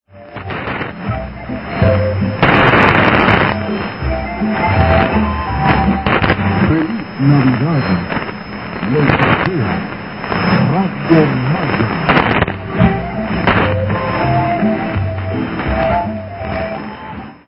Radio Maya, 3325 kHz, as heard in Davenport, Iowa on 25 November 2001 at 1045 UTC: